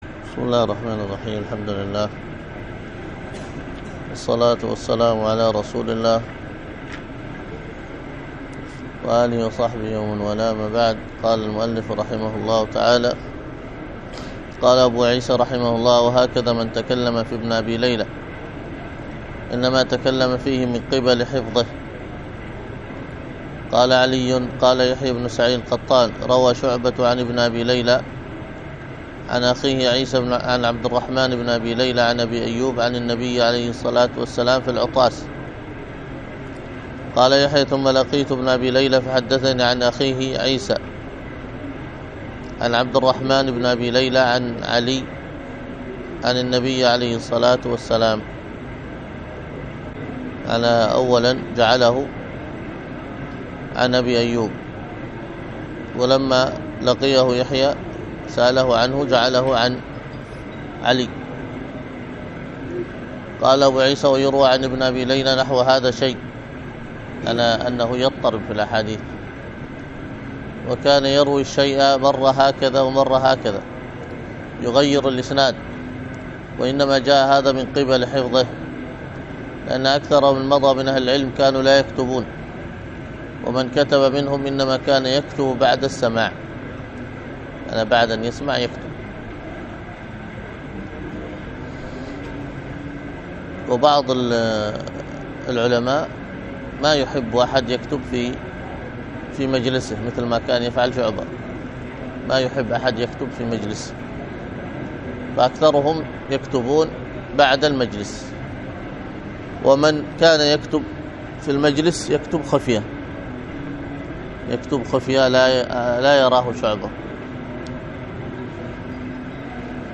الدروس الحديث وعلومه